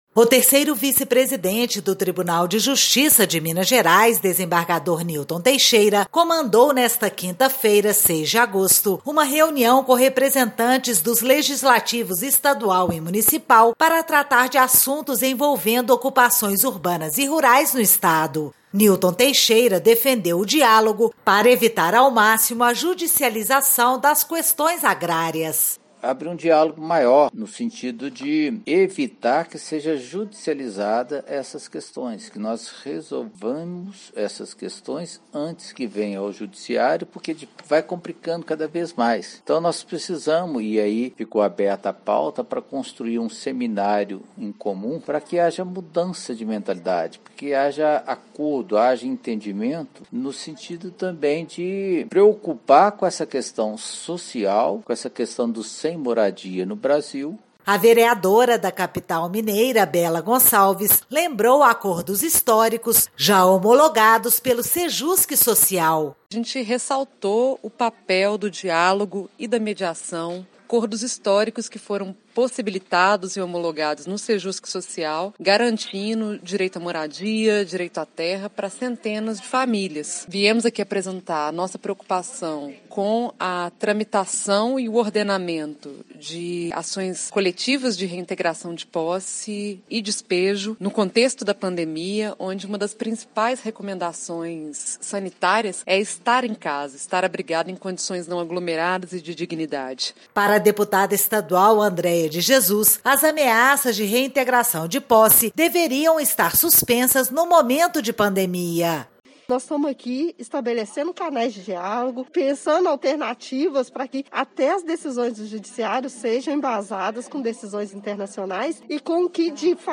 Em anexo, ouça o podcast com os áudios do desembargador Newton Teixeira e das parlamentares.